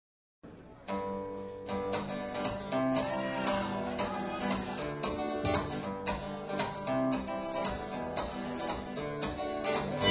je dirais (sans guitare pour vérifier) : G - C - Em - D en boucle
D [xx0232] (et dernier D on rajoute la quarte => D [xx0233]